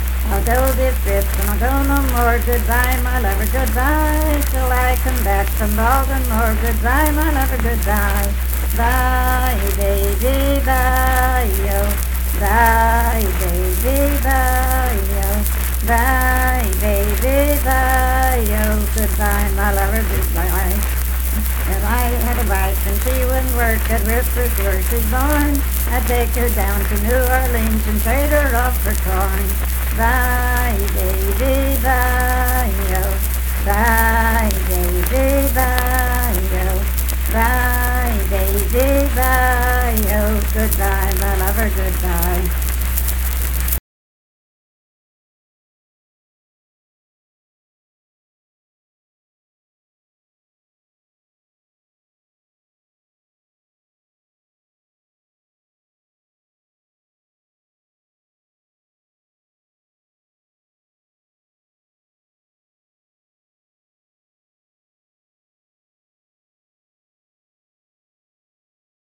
Unaccompanied vocal music performance
Children's Songs
Voice (sung)